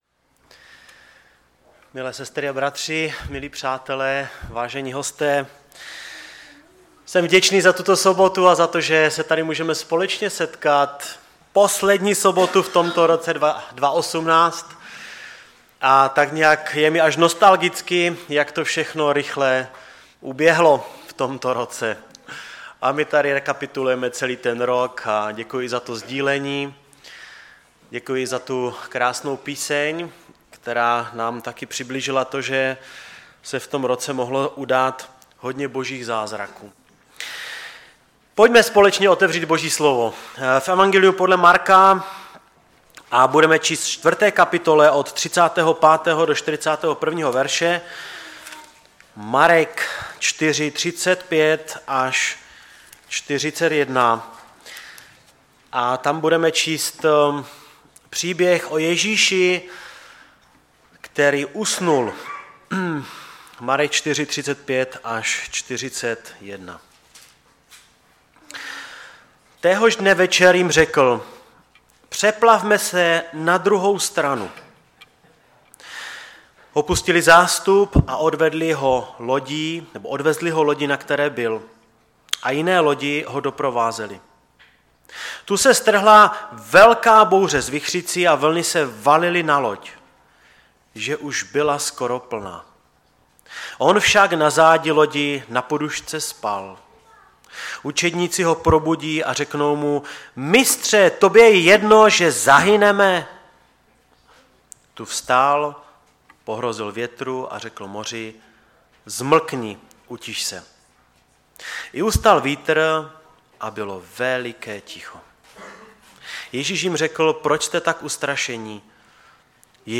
Bible Text: Marek 4,35-41 | Preacher